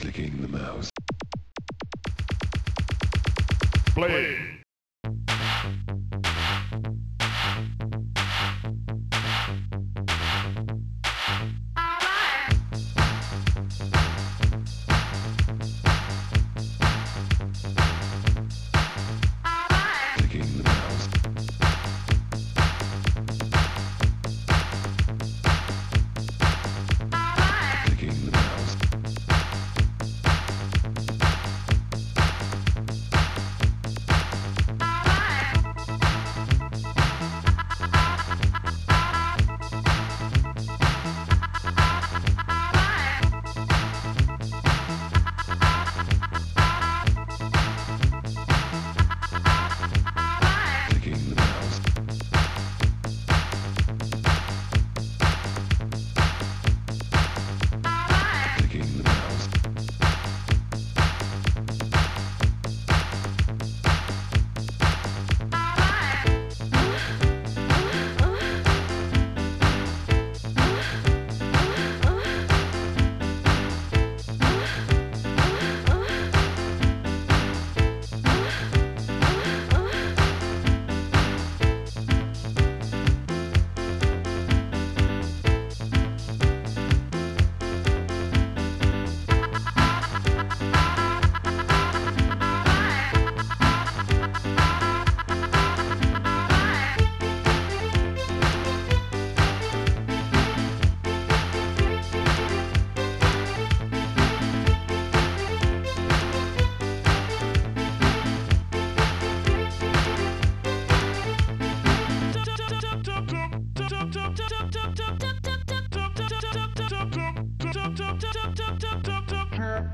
Protracker Module
2 channels